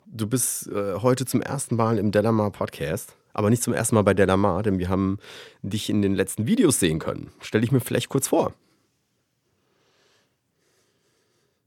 Sie klingt schon recht gut, wurde in diesem Fall in meinem damaligen Wohnzimmer aufgezeichnet.
Aufgrund der Nähe zum Mikrofon hört man am Ende der Datei (und in allen anderen Sprechpausen) mein Atmen.
• Hochpass-Filter ab 80 Hz
• Tiefpass-Filter ab 18 kHz